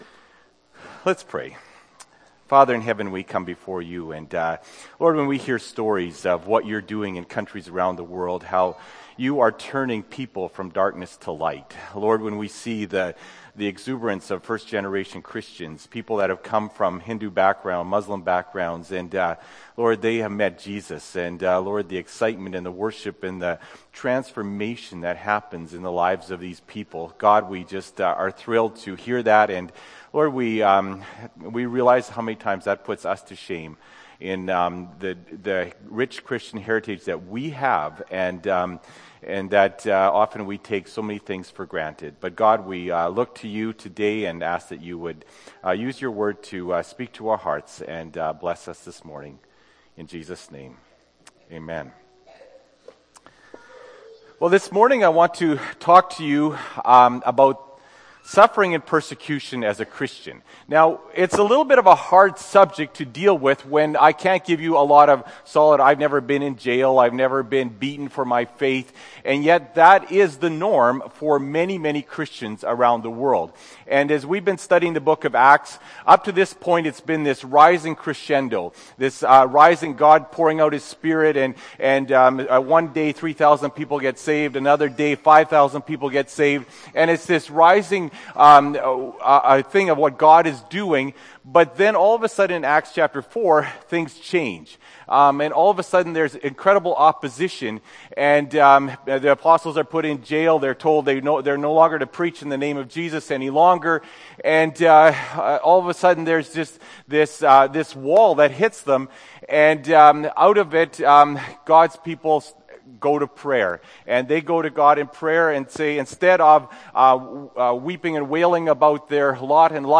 Feb. 2, 2014 – Sermon